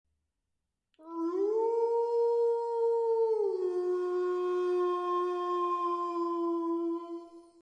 Download Wolf sound effect for free.
Wolf